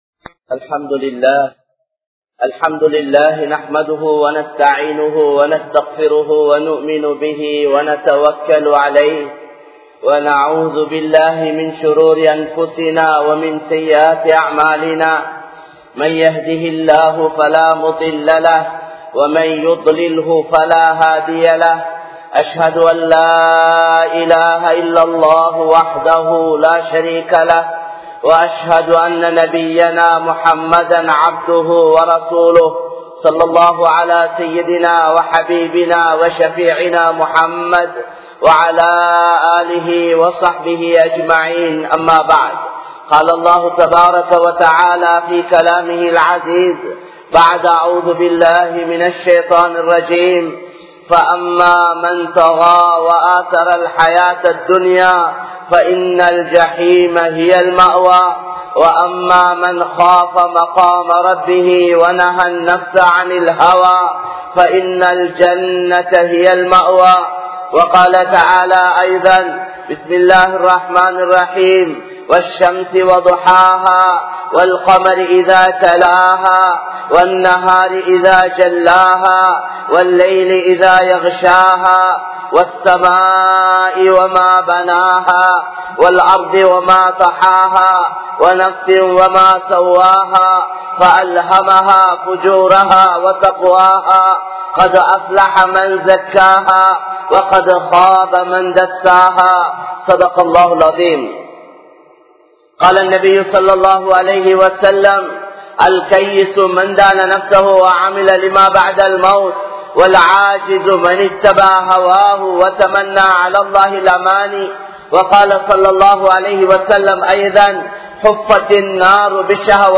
Paavaththai Niruththungal (பாவத்தை நிறுத்துங்கள்) | Audio Bayans | All Ceylon Muslim Youth Community | Addalaichenai
Colombo 12, Aluthkade, Muhiyadeen Jumua Masjidh